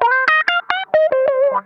ITCH LICK 7.wav